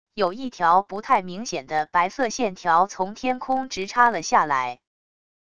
有一条不太明显的白色线条从天空直插了下来wav音频生成系统WAV Audio Player